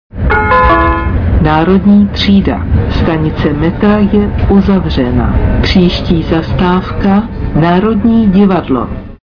- Hlášení v tramvajích si